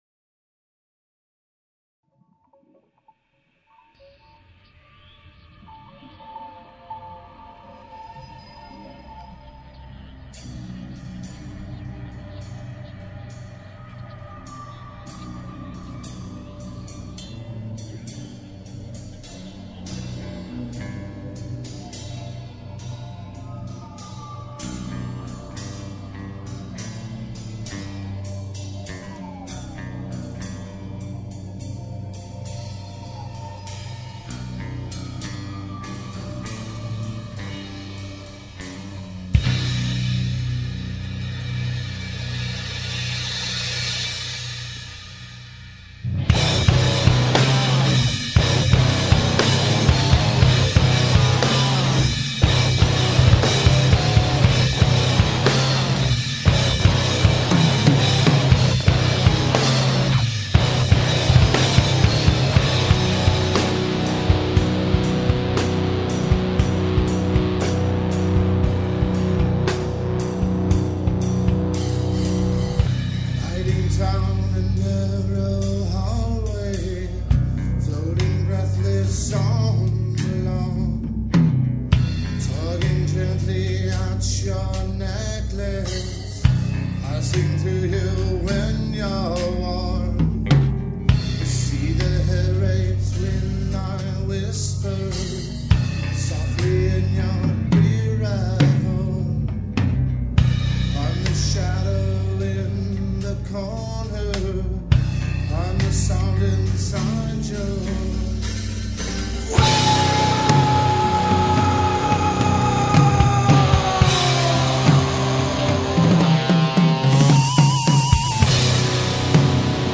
dance/electronic
Heavy metal
Experimental
Nu-metal